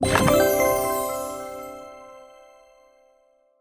sound_chaTu_baoXiang_daKai.mp3